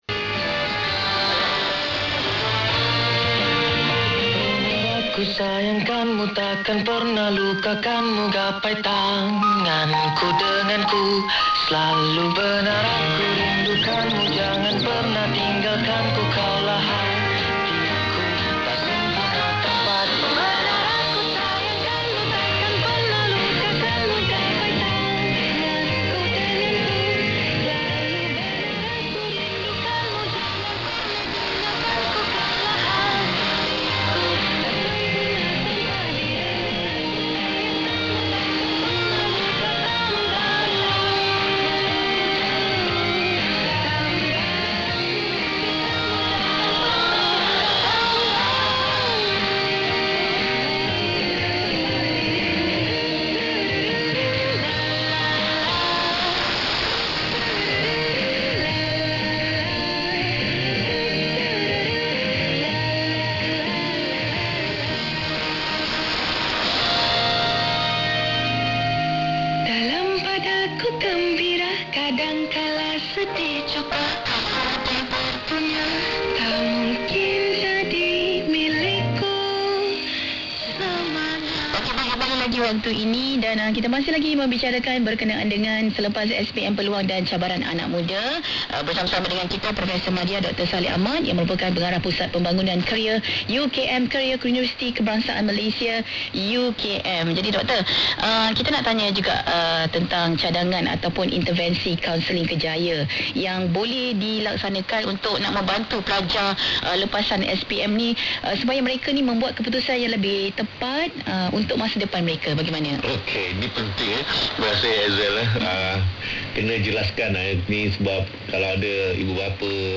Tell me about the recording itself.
These are both coming from the Kajang Transmitting Station near Kuala Lumpur. Nasional FM April 2, 2026 on 15295 kHz at 0255 UTC: